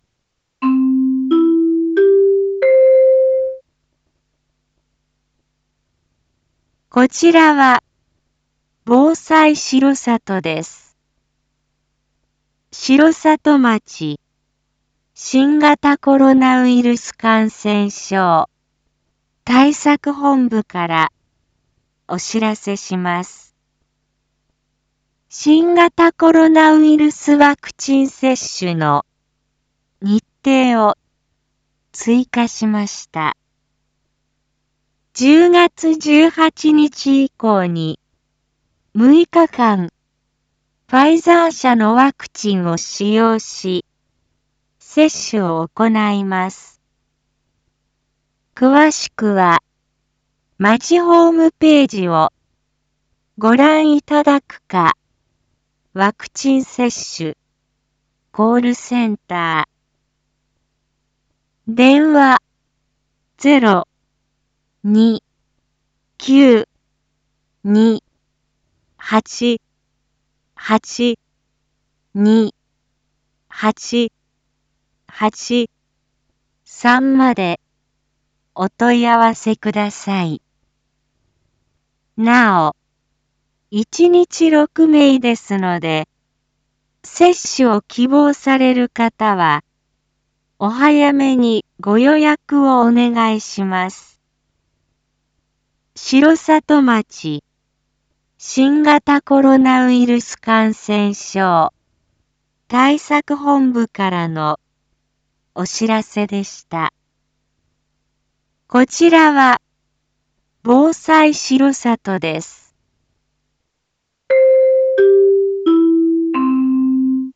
一般放送情報
Back Home 一般放送情報 音声放送 再生 一般放送情報 登録日時：2021-09-22 07:01:58 タイトル：Ｒ３．９．２１ １９時 インフォメーション：こちらは防災しろさとです。